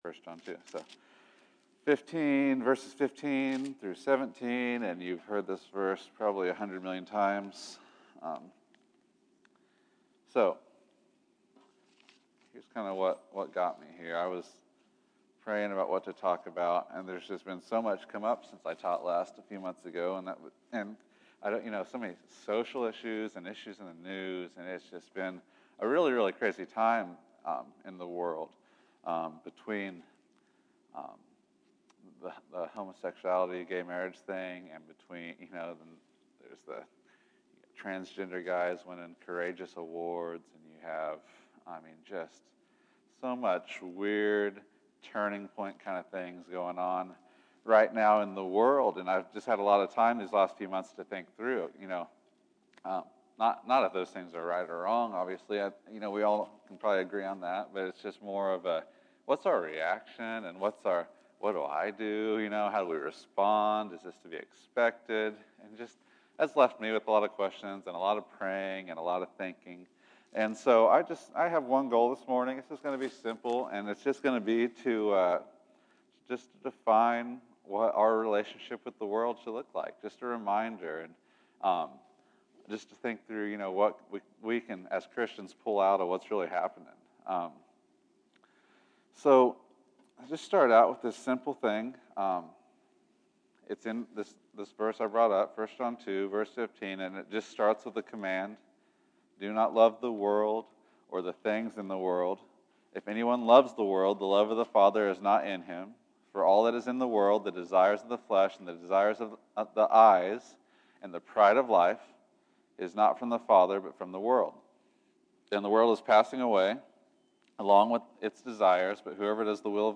Loving God Instead of Fearing Man July 26, 2015 Category: Sunday School | Location: El Dorado Back to the Resource Library How loving the world turns into the fear of man and how both are conquered by loving God.